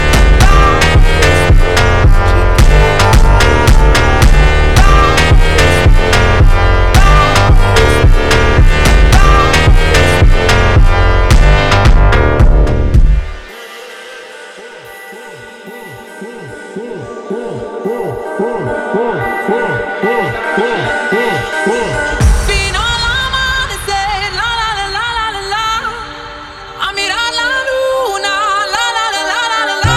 Electronic Electronica
Жанр: Электроника